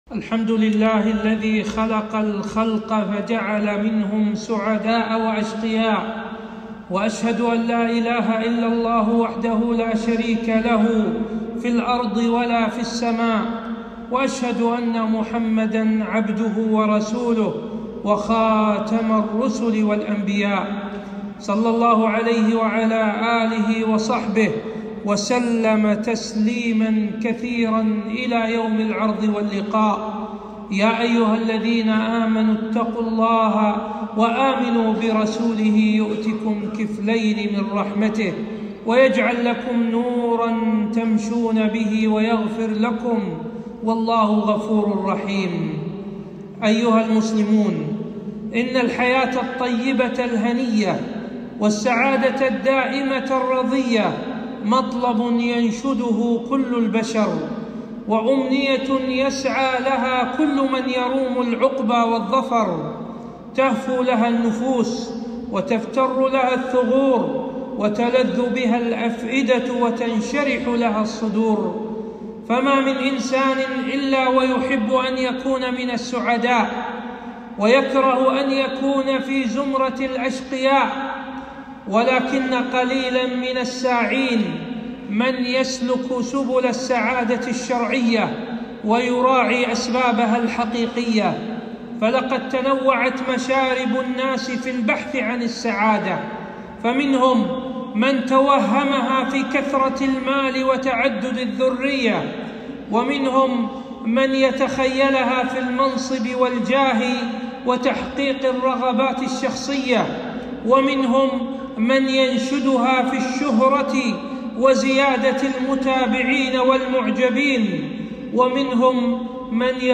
خطبة - أسعدُ الناس